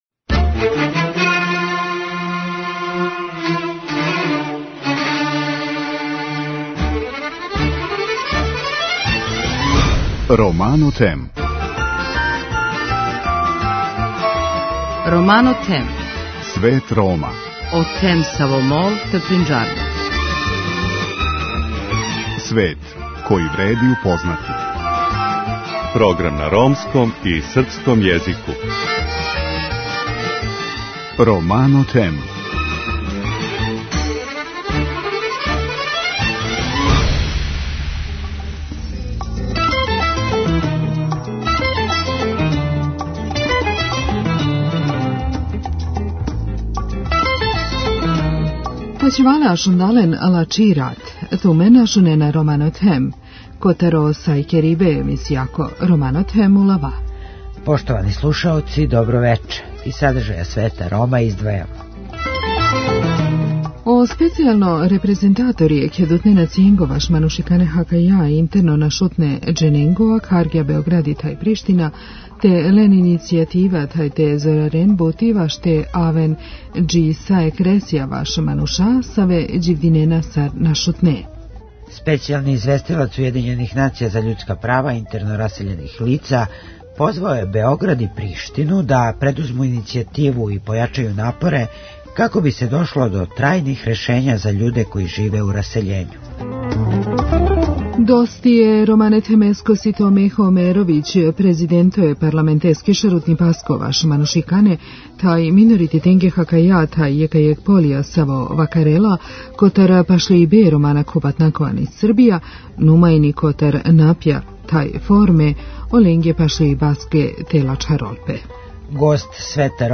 Гост Света Рома је Мехо Омеровић председник скупштинског Одбора за људска и мањинска права и равноправност полова који говори о положају ромске заједнице у Србији али и о мерама и начинима да се њиховог положај побољша.